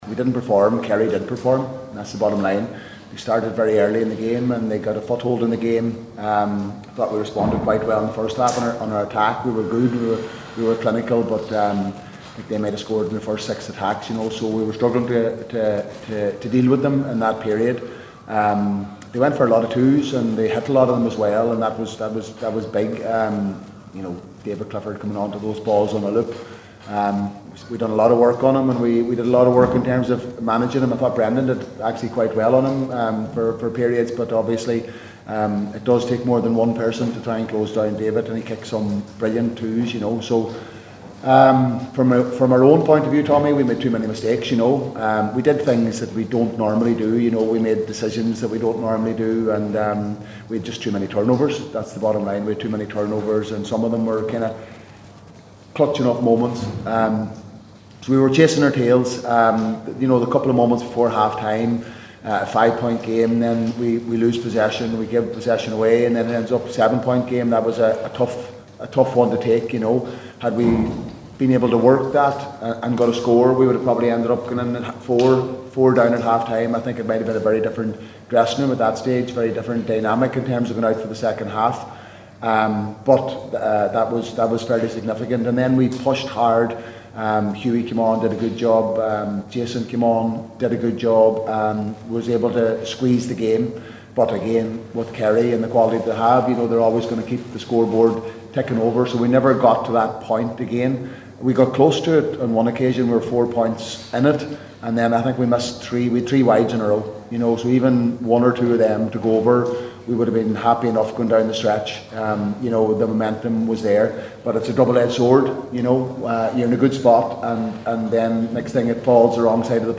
Jim McGuinness’ full press conference after Kerry defeat
Donegal boss Jim McGuinness spoke to the assembled media after the defeat…